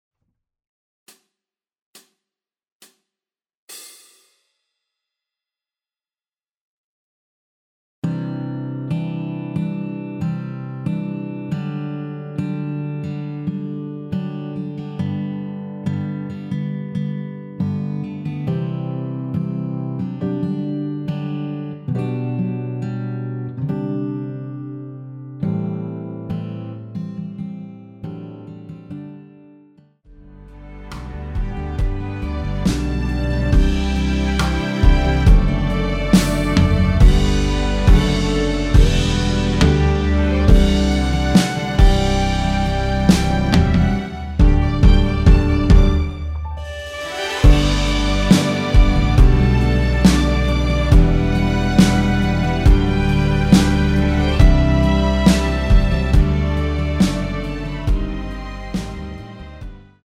전주 없이 시작하는 곡이라서 시작 카운트 만들어놓았습니다.(미리듣기 확인)
원키에서(-1)내린 MR입니다.
앞부분30초, 뒷부분30초씩 편집해서 올려 드리고 있습니다.